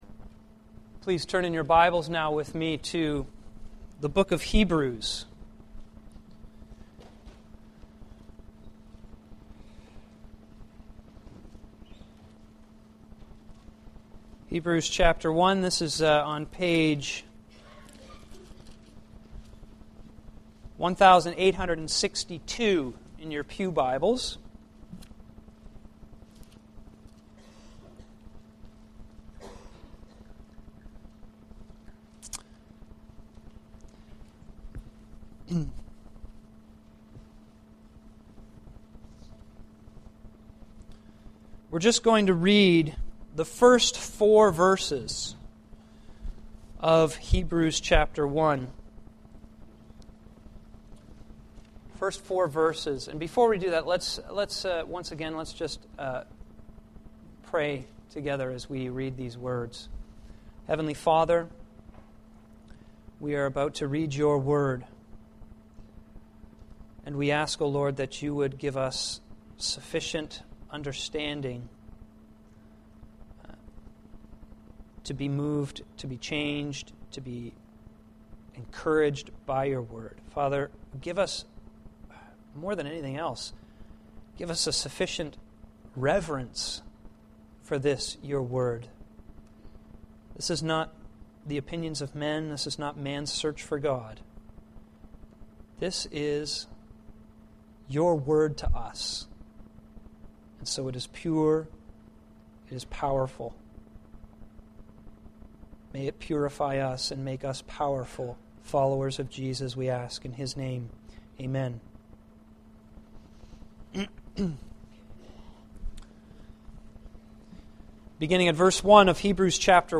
Sermon Library